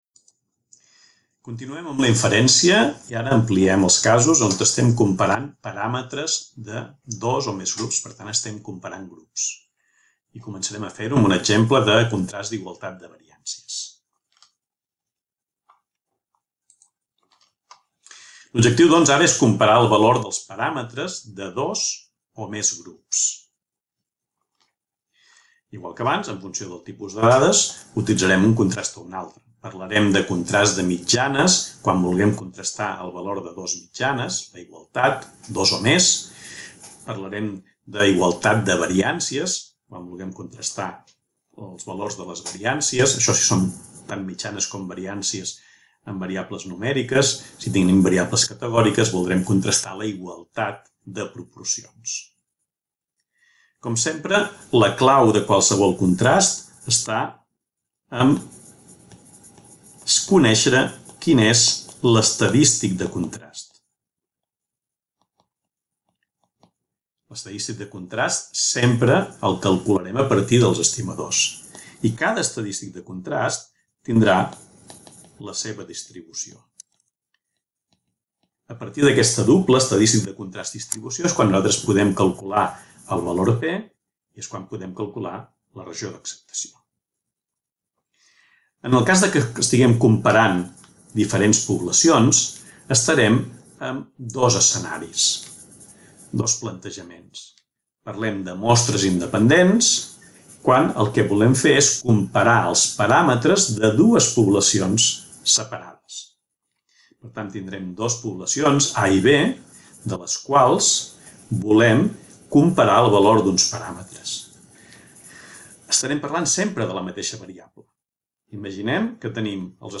Statistical Theory lesson on inferences to compare groups and contrasts of equality of variances